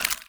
Sfx_creature_trivalve_scuttle_slow_front_legs_03.ogg